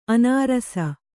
♪ anārasa